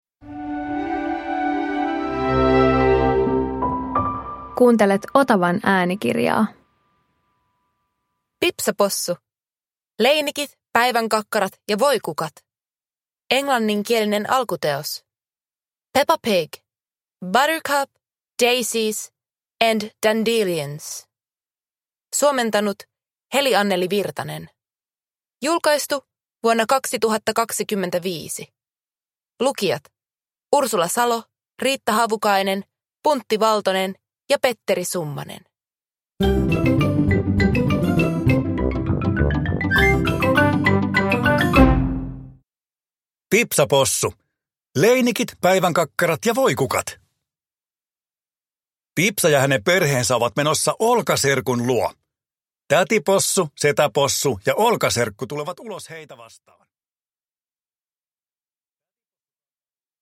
Pipsa Possu - Leinikit, päivänkakkarat ja voikukat – Ljudbok